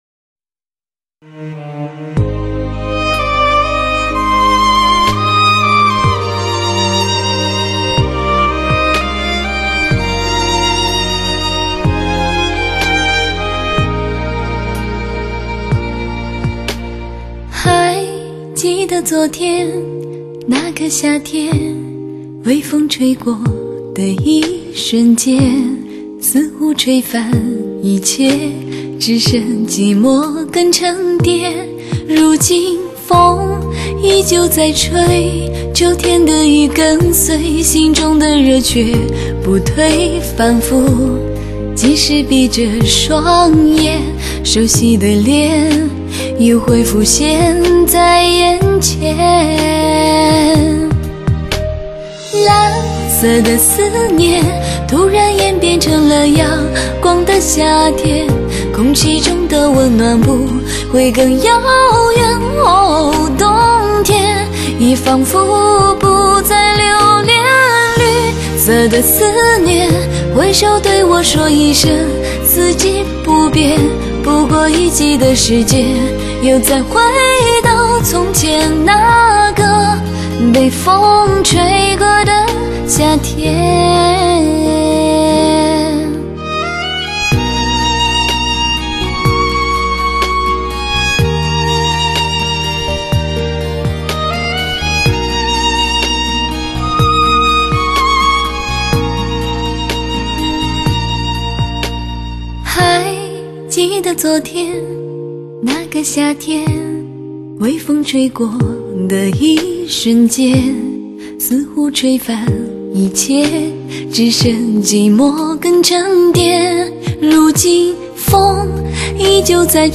她的声音不仅圆润、甘甜、 深情、柔美，更多了一份女人的矜持，平添了一份美丽女人的含蓄和娴雅。